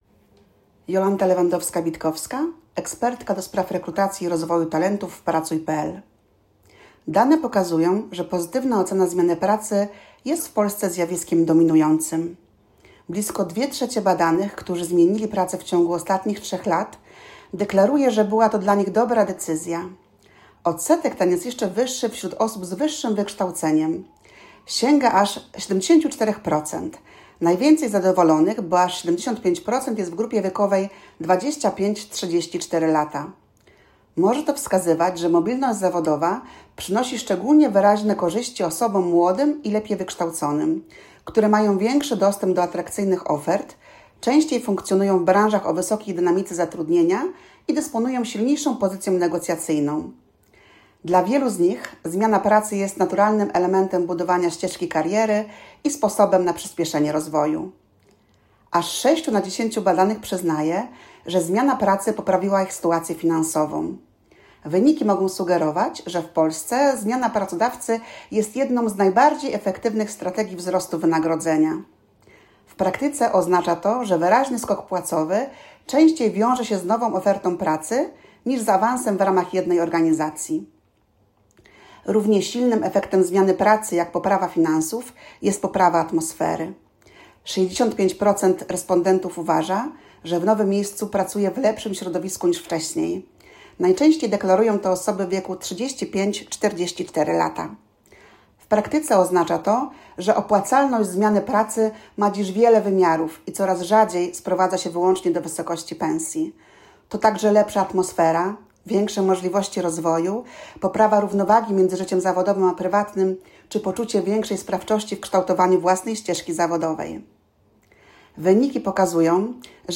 Skorzystaj z komentarza AUDIO